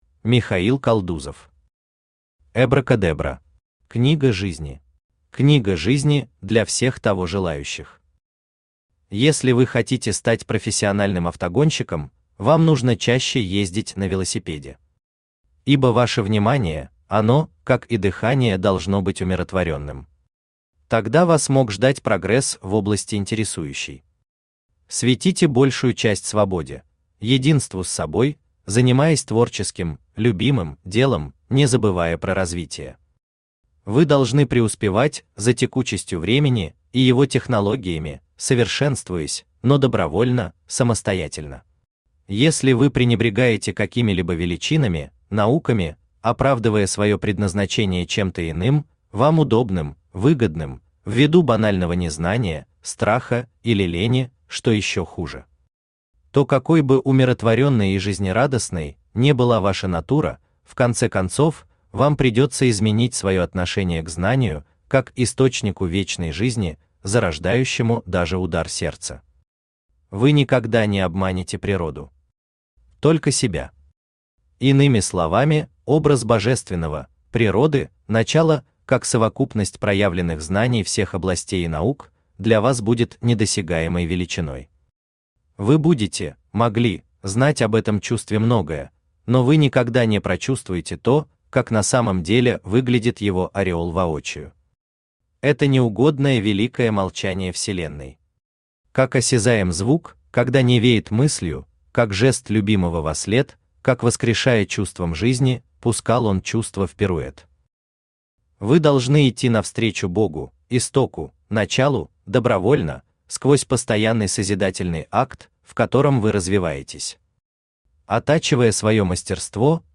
Книга жизни Автор Михаил Константинович Калдузов Читает аудиокнигу Авточтец ЛитРес.